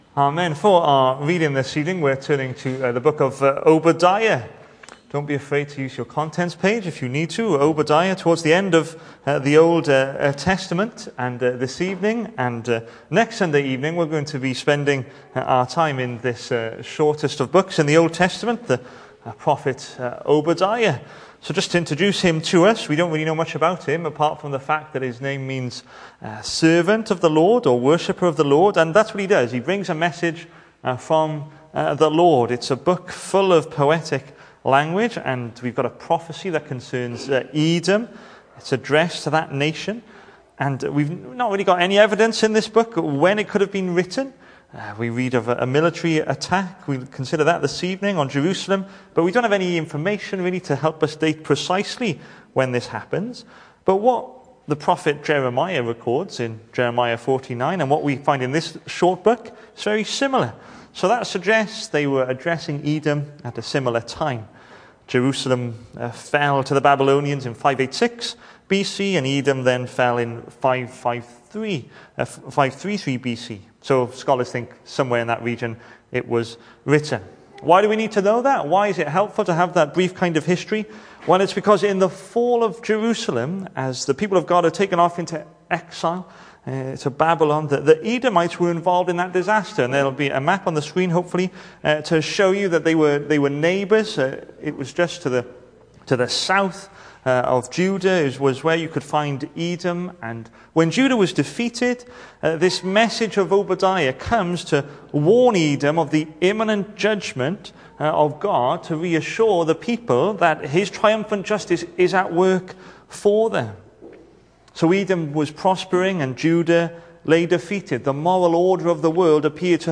The 6th of July saw us hold our evening service from the building, with a livestream available via Facebook.